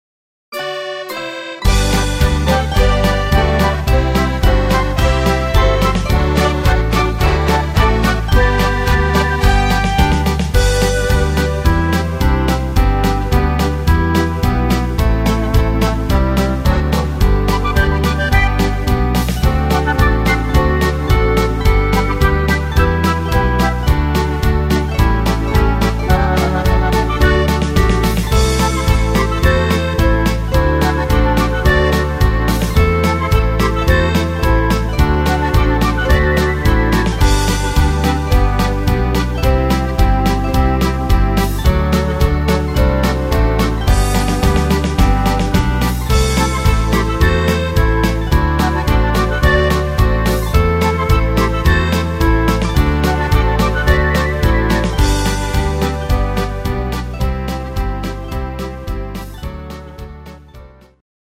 gesungene Version